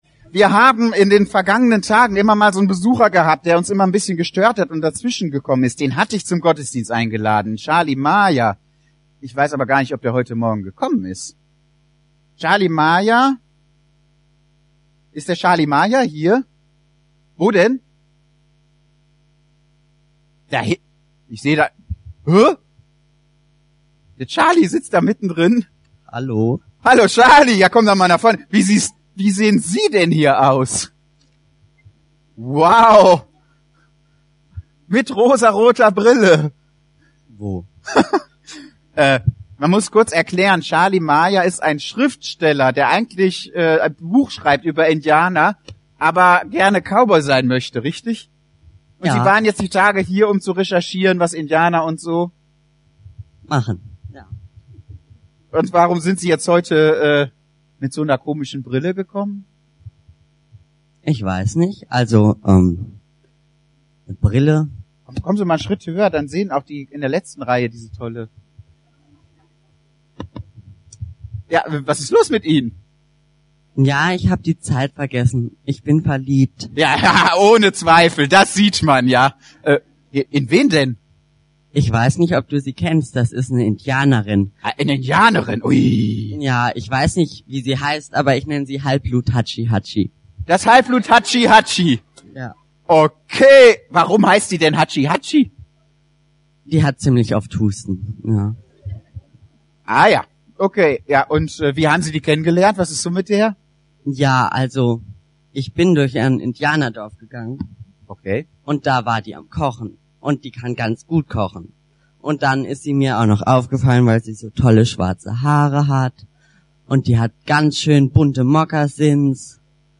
Familiengottesdienst